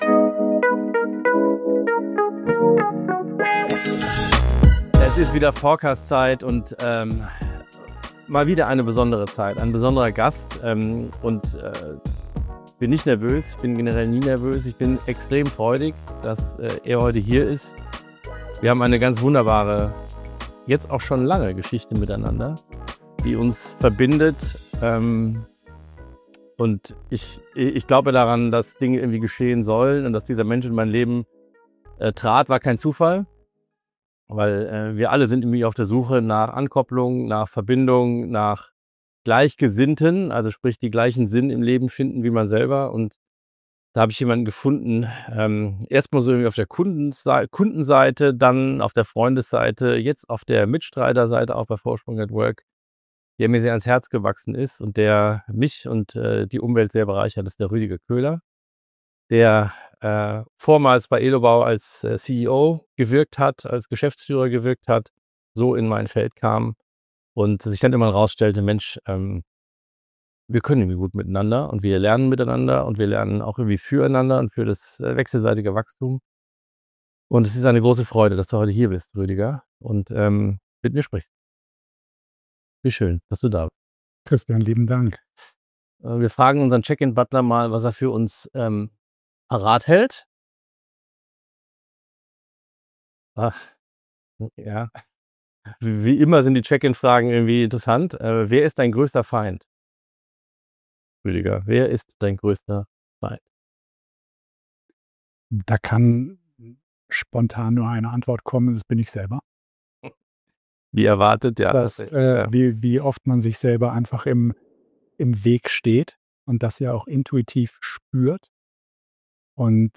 Zwei Männer im Dialog über Lebensumbrüche, neue Perspektiven im „dritten Quartal“ und die Kunst, sich selbst treu zu bleiben – trotz aller Konditionierungen. Ein Gespräch über Selbstführung, Mut und die Kraft, neu anzufangen.